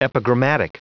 Prononciation du mot epigrammatic en anglais (fichier audio)
Prononciation du mot : epigrammatic